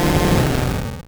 Cri d'Abo dans Pokémon Rouge et Bleu.